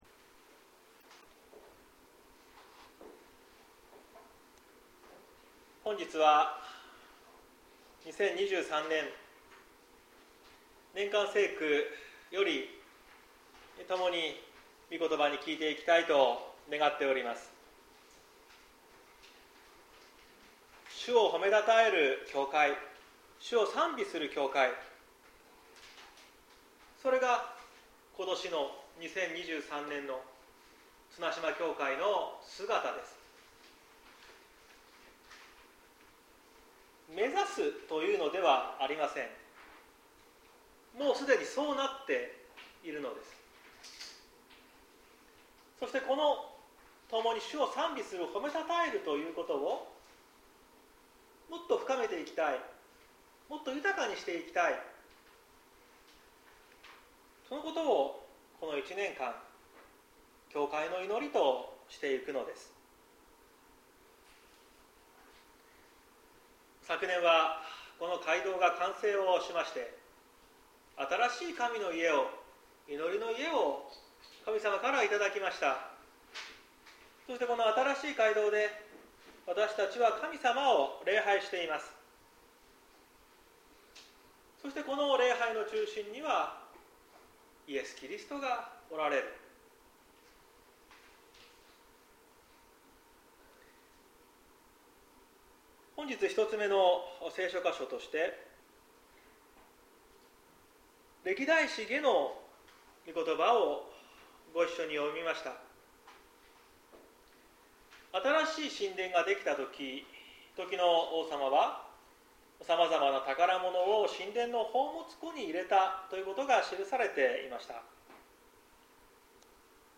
2023年01月29日朝の礼拝「主をほめたたえる教会」綱島教会
綱島教会。説教アーカイブ。